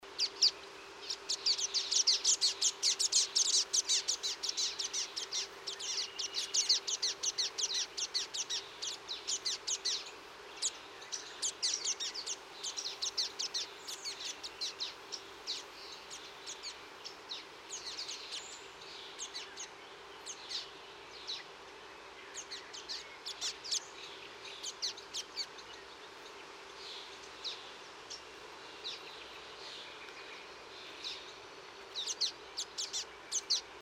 Estrilda astrild.mp3